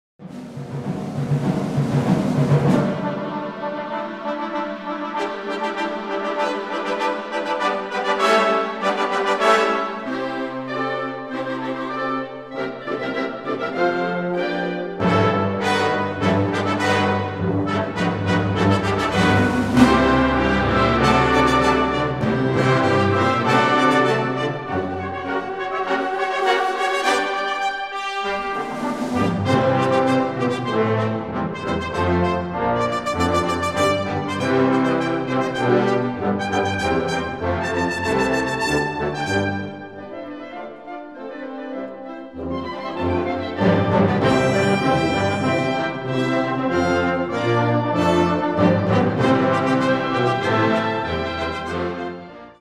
Categoria Concert/wind/brass band
Sottocategoria Suite
Instrumentation Ha (orchestra di strumenti a faito)